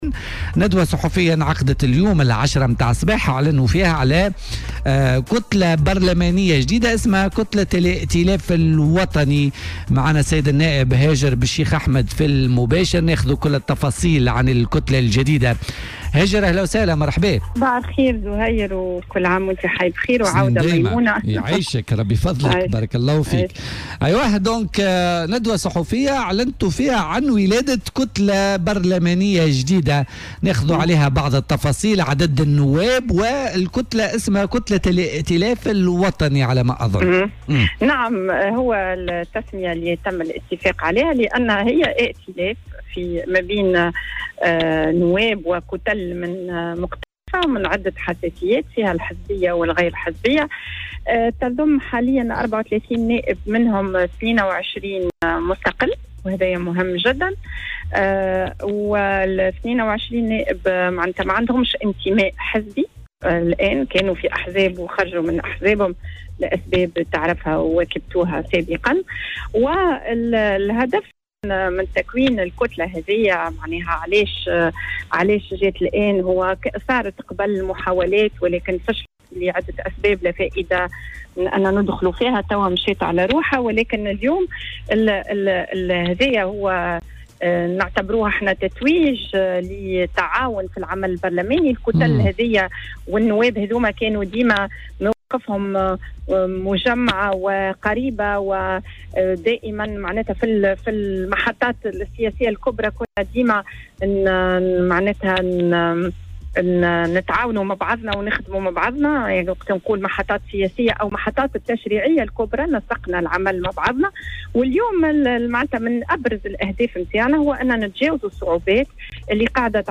وأضافت في مداخلة هاتفية مع "بوليتيكا" أن الكتلة تضم 34 نائبا بينهم 22 نائبا مستقلا.